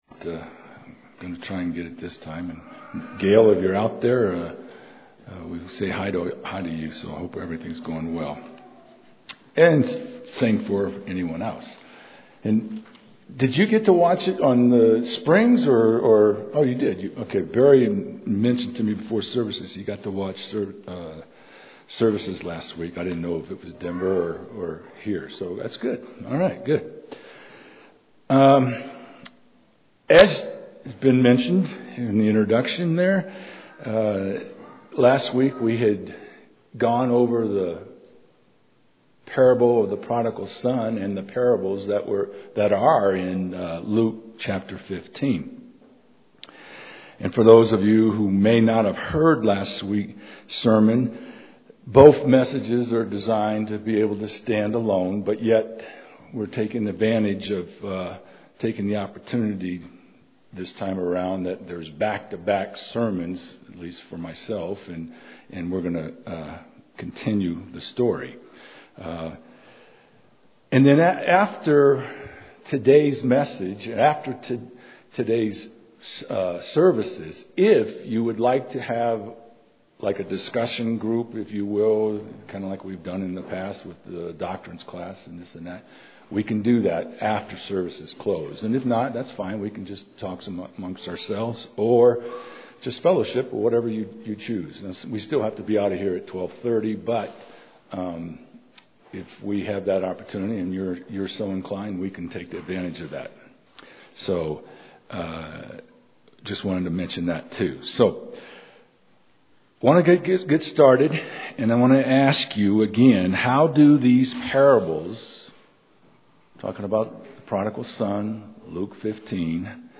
This sermon continues with lessons from Luke 15, combining with other scriptures and parables what God has in mind for His church and all mankind.